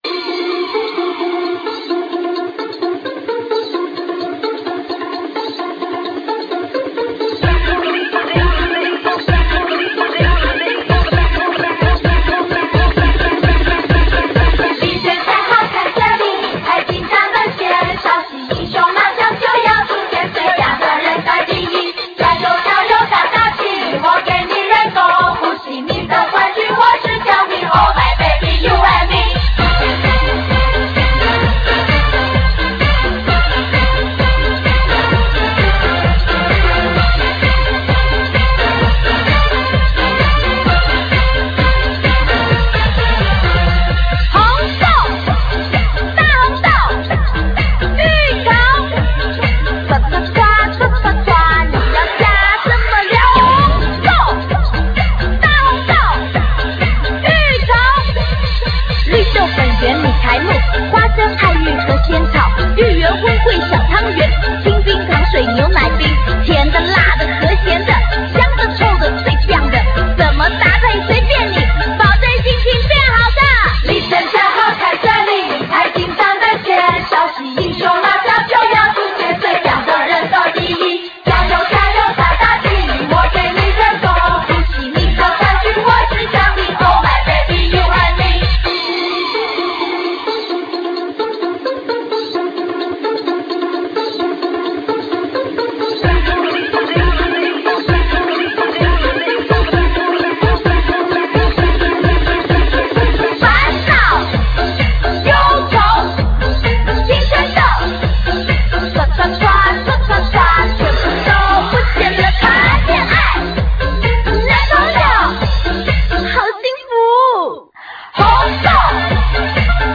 La musique commerciale
(pas de très bonne qualité, mais bien compressé)